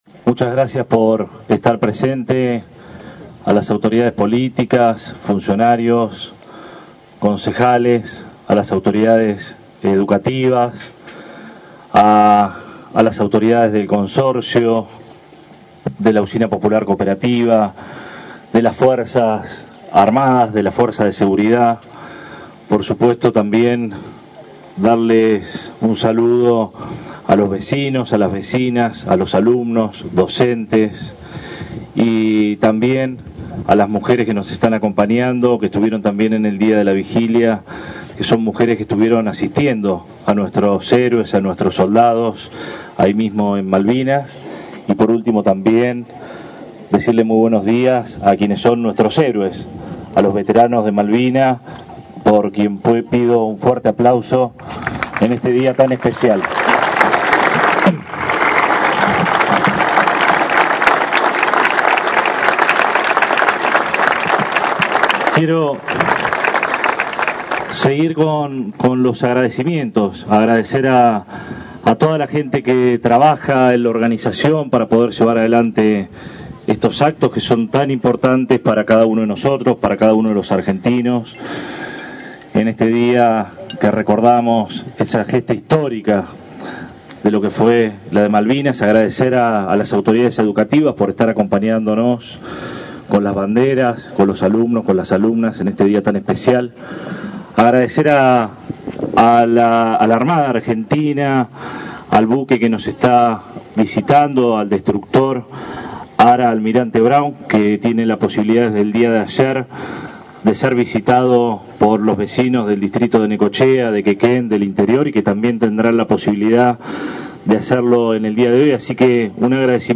A 43 años del conflicto del Atlántico Sur se realizó el acto conmemorativo en el imponente monumento a la Gesta de Malvinas en Quequén, encabezado por el Intendente Arturo Rojas y los excombatientes de nuestro distrito, en un nuevo aniversario del Día del Veterano y de los Caídos en la Guerra de Malvinas.
Al dirigirse al público, el Intendente Rojas afirmó que más que resaltar anécdotas o acontecimientos que sucedían durante la guerra, prefirió enfatizar en lo que pasó posteriormente a la misma.
02-04-AUDIO-Arturo-Rojas.mp3